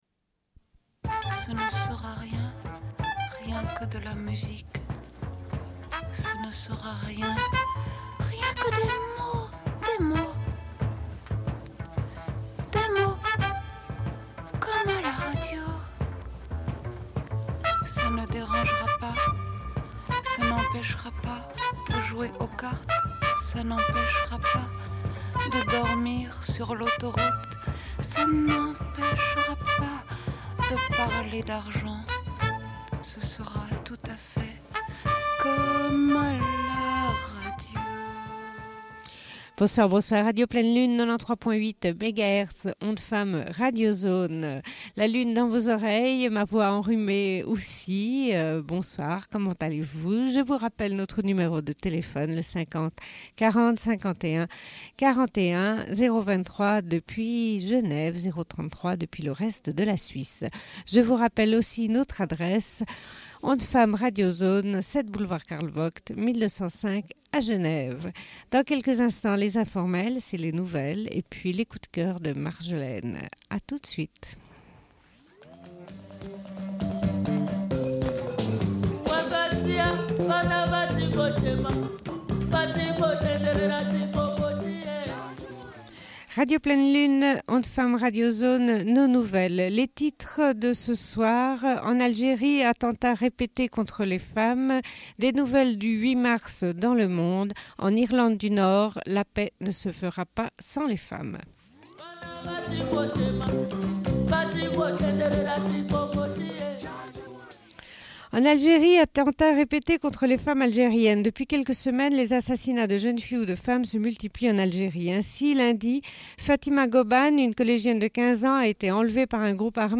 Bulletin d'information de Radio Pleine Lune du 15.03.1995 - Archives contestataires
Une cassette audio, face B